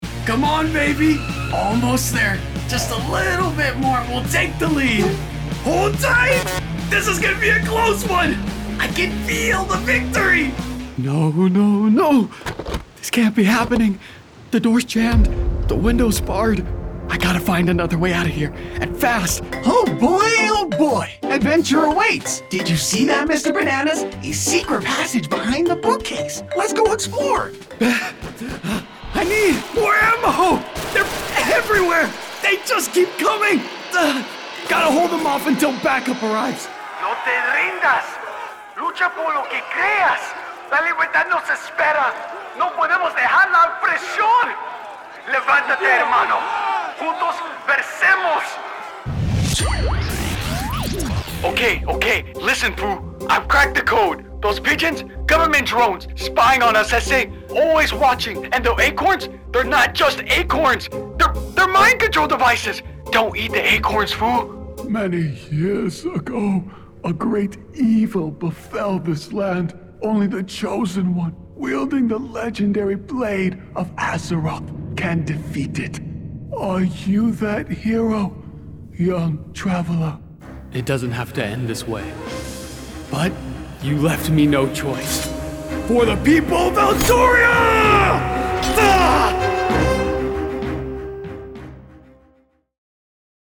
Professional Male Voice Over Talent